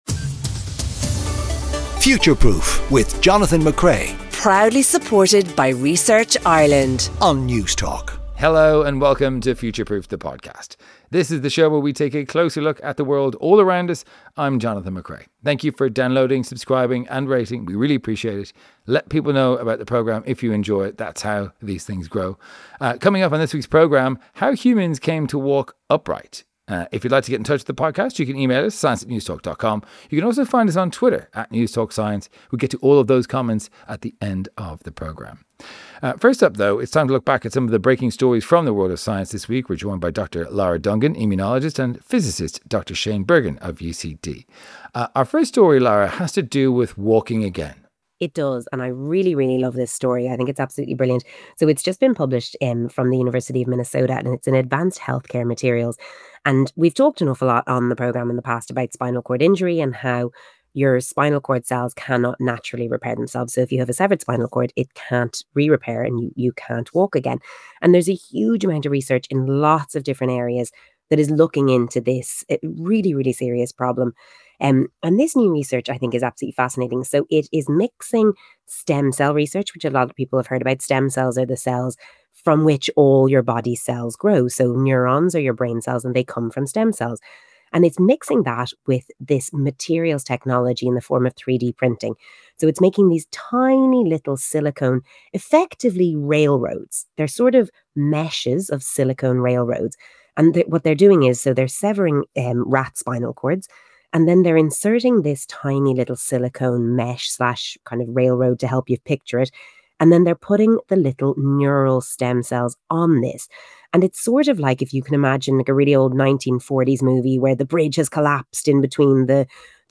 is joined in studio for all the latest science stories for Newsround and speaks to one of our two guests featured on the show.